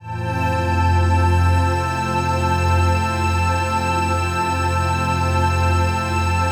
TRANCPAD03-LR.wav